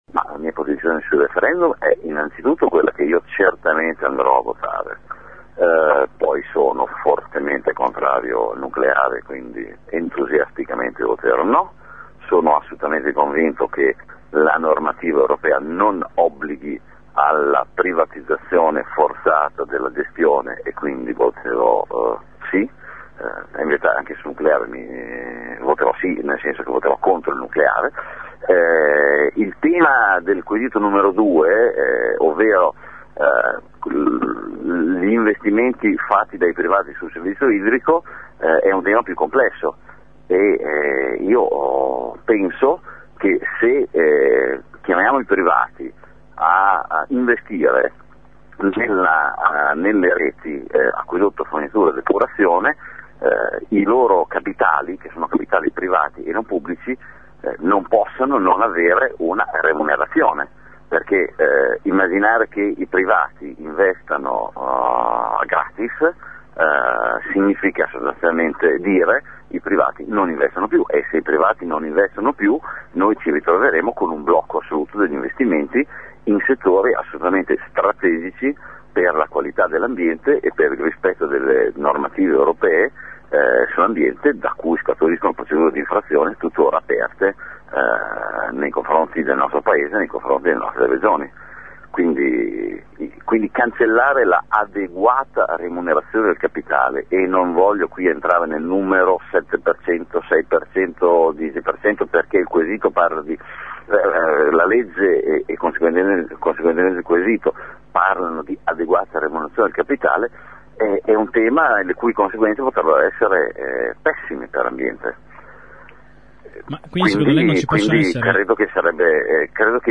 Intervistato ai nostri miocrofoni Burgin ha spiegato che andrà a votare tre sì, ma il secondo quesito sull’acqua , secondo l’assessore, “E’ più complesso” e “immaginare che i privati che investano a gratis significa che i provati non investono più”.